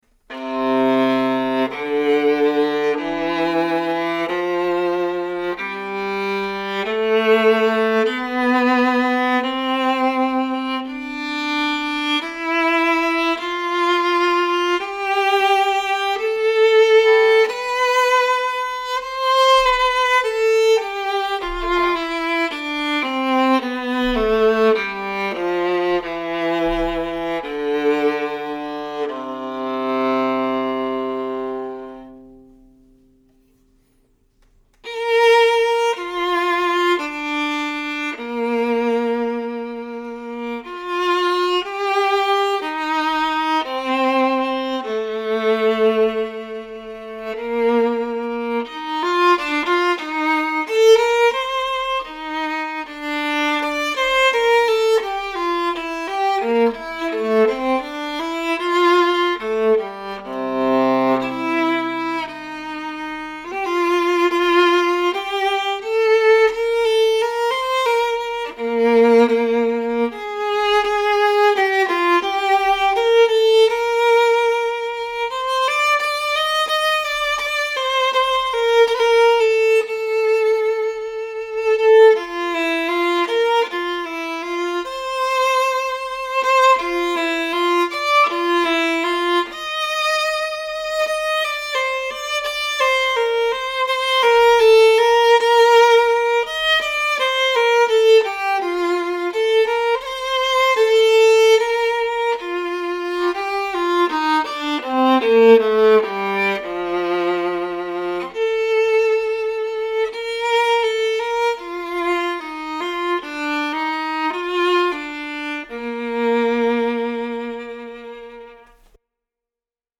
Jay Haide 16″ viola
Maggini model viola.